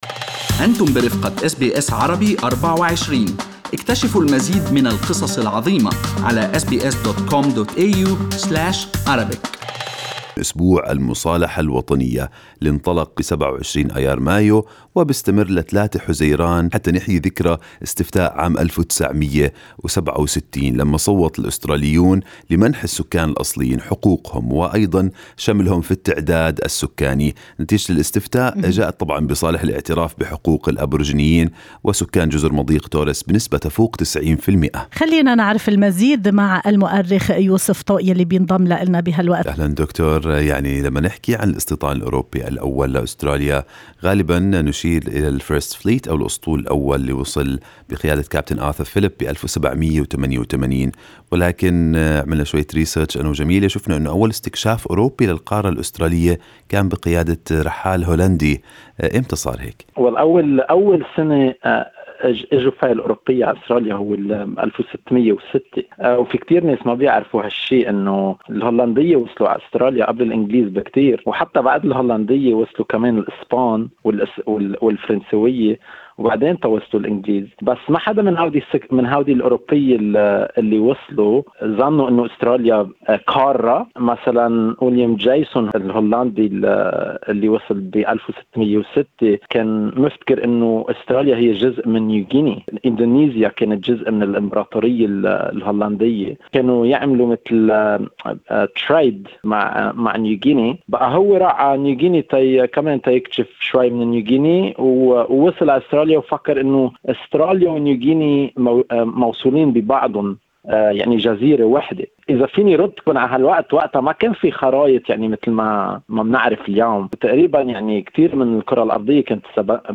في حديث لأس بي أس عربي24